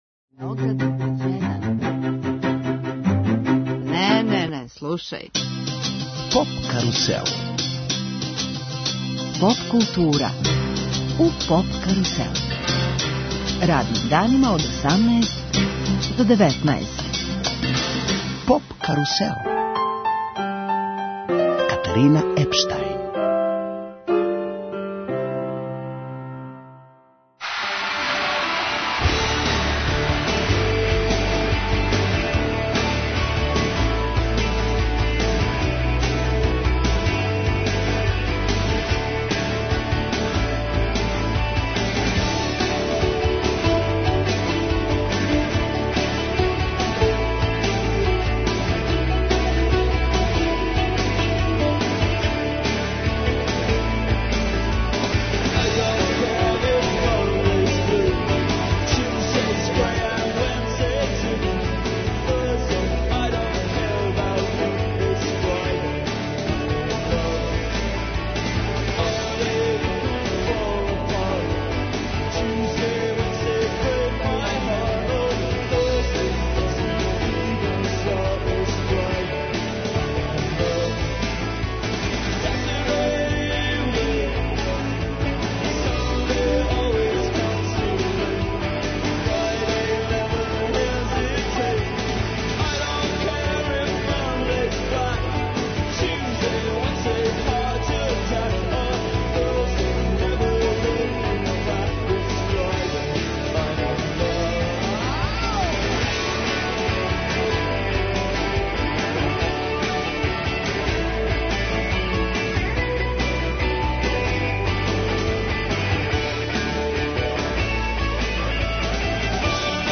Поп карусел емитује се уживо, са фестивала EXIT.